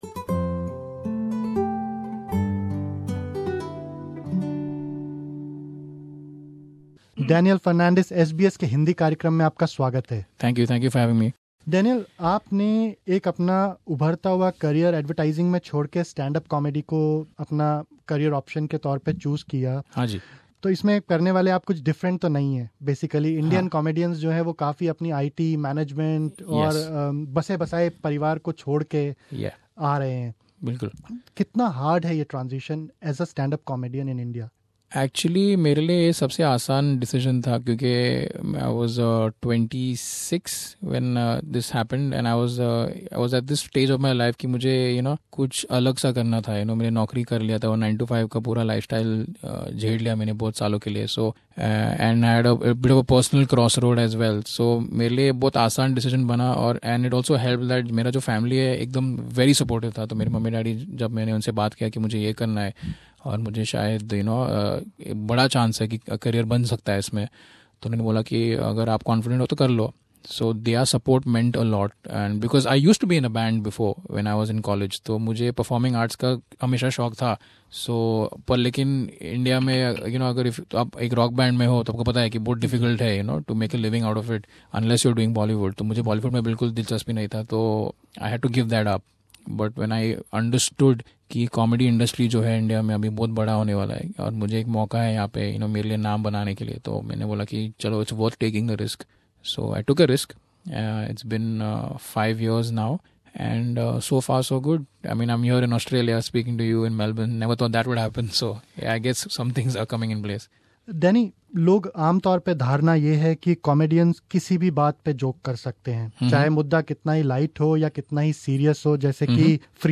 at SBS Hindi studio
interview